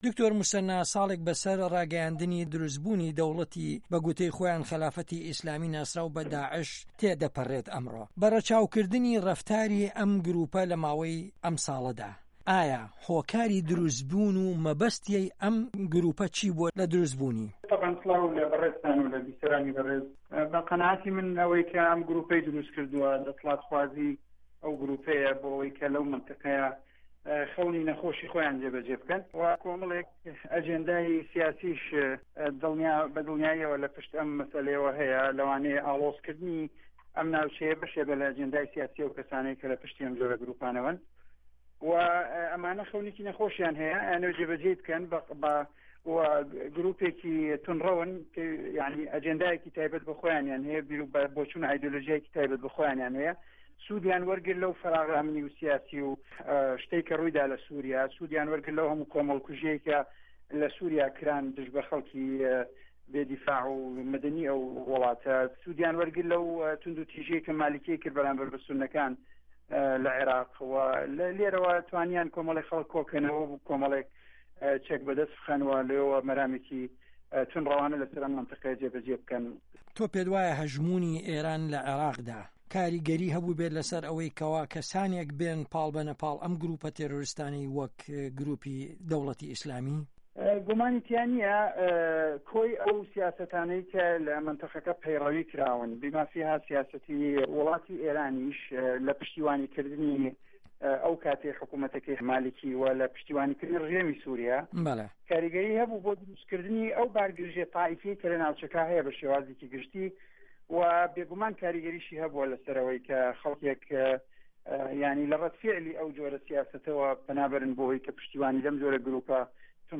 وتوێژ له‌گه‌ڵ موسه‌نا ئه‌مین